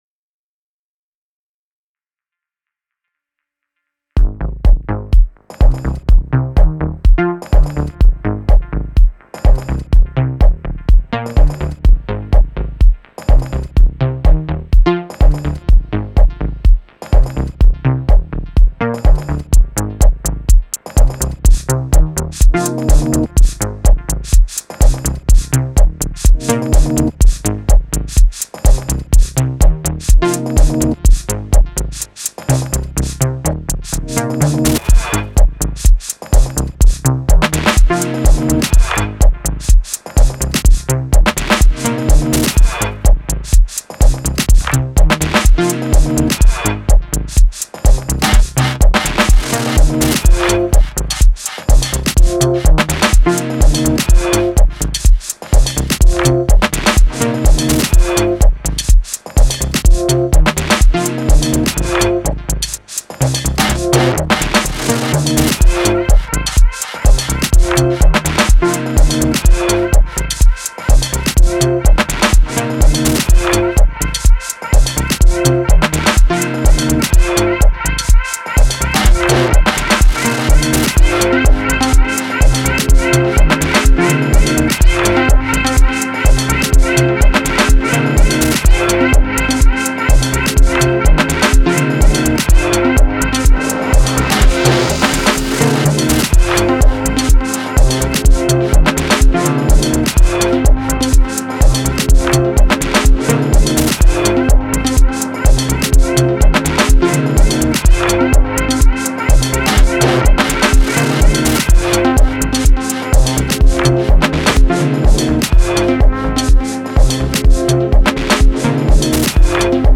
one more/// chill